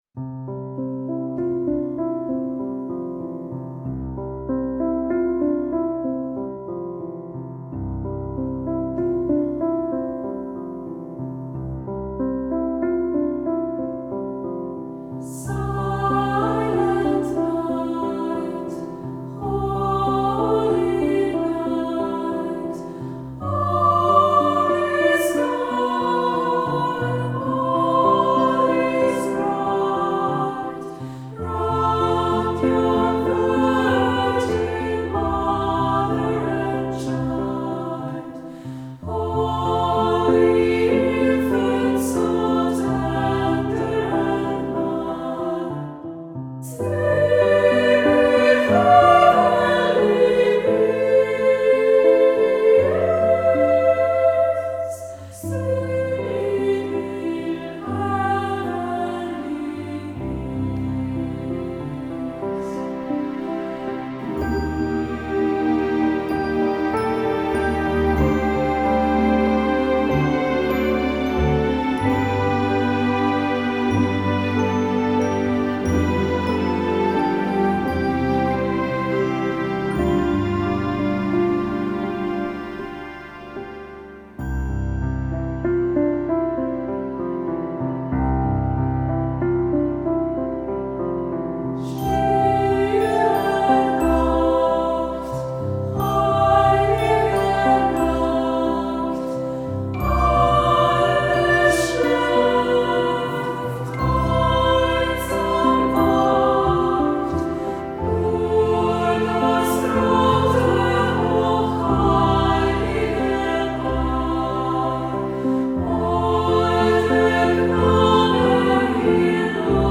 Instrumente - Piano, Mixed Choir Tempo - Medium BPM - 90